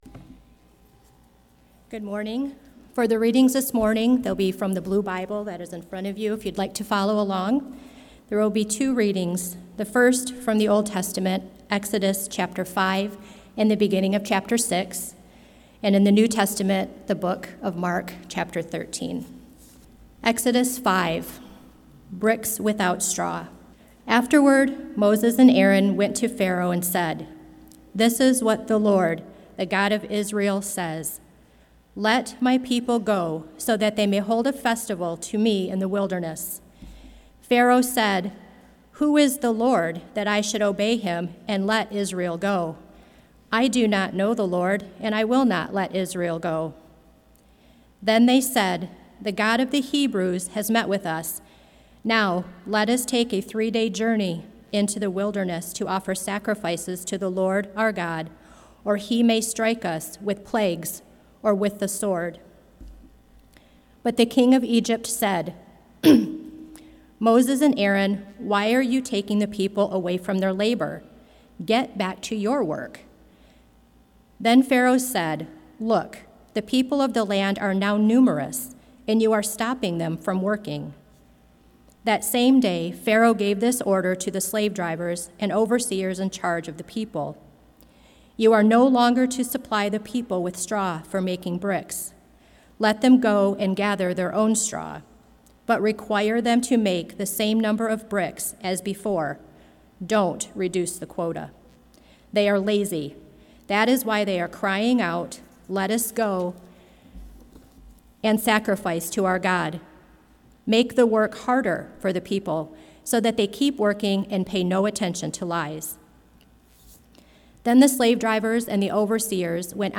Blended Worship Gathering